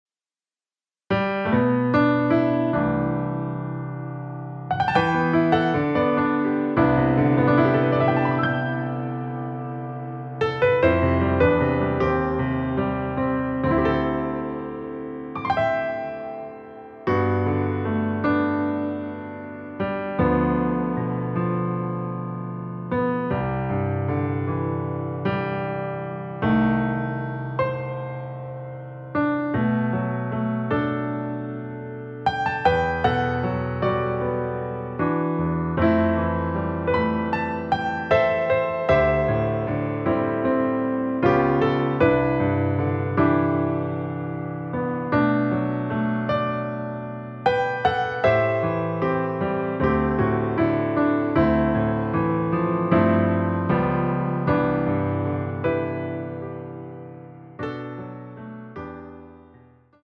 앞부분30초, 뒷부분30초씩 편집해서 올려 드리고 있습니다.